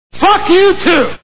Scent of a Woman Movie Sound Bites